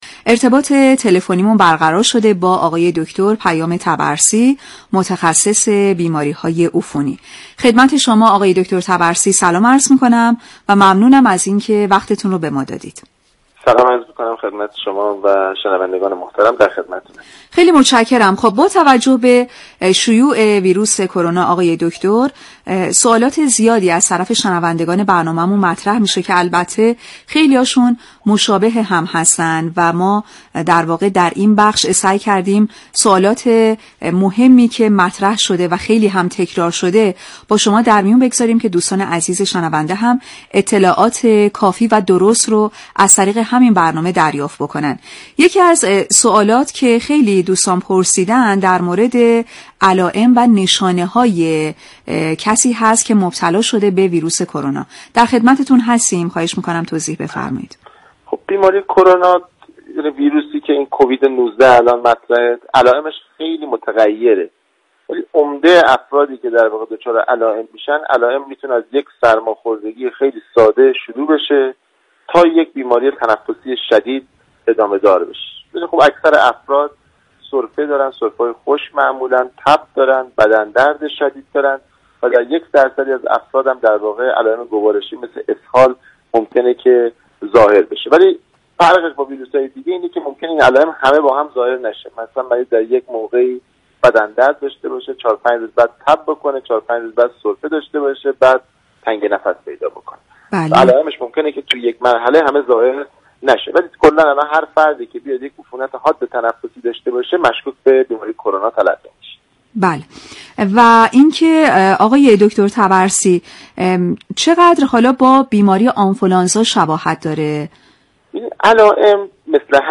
برنامه سلامت باشیم، ویژه برنامه ویروس كرونا، هر روز ساعت 8:30 به مدت 25 دقیقه از شبكه رادیویی ورزش روی آنتن می رود.